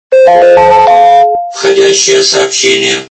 При прослушивании Входящее - СМС качество понижено и присутствуют гудки.
Звук Входящее - СМС